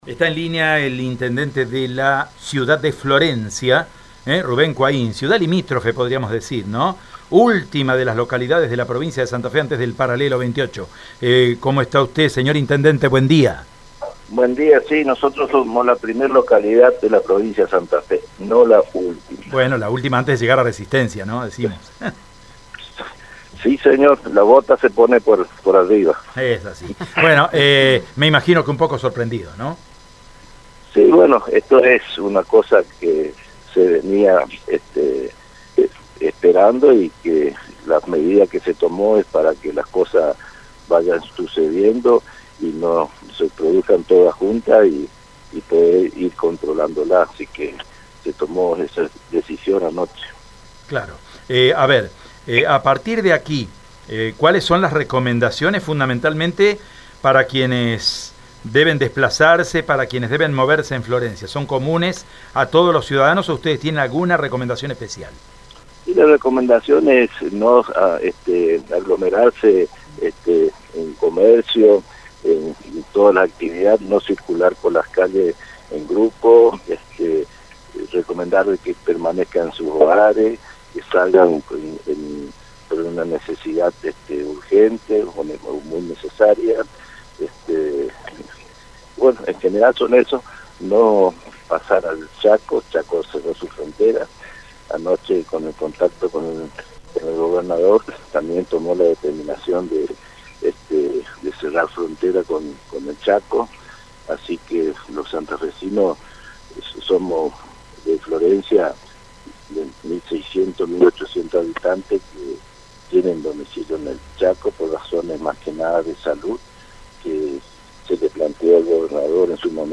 Rubén Quain en Radio EME:
ruben-quain-intendente-de-florencia.mp3